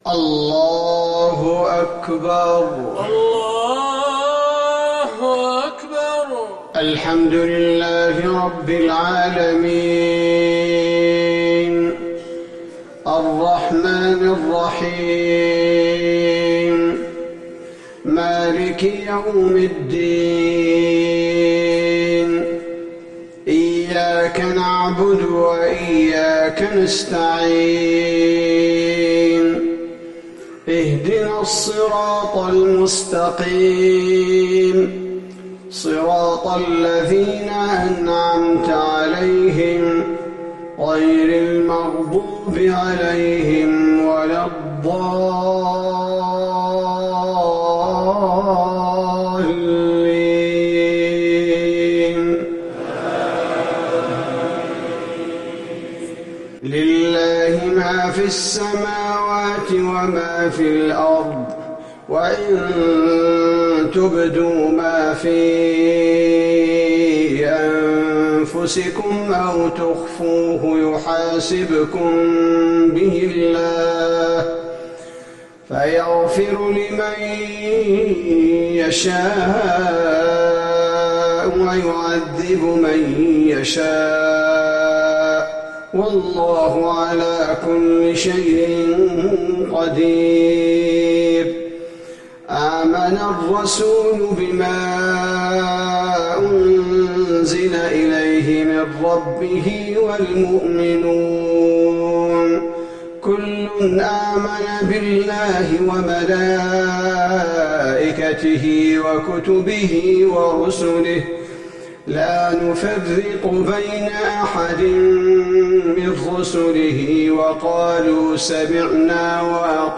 صلاة المغرب للقارئ عبدالباري الثبيتي 6 جمادي الآخر 1441 هـ
تِلَاوَات الْحَرَمَيْن .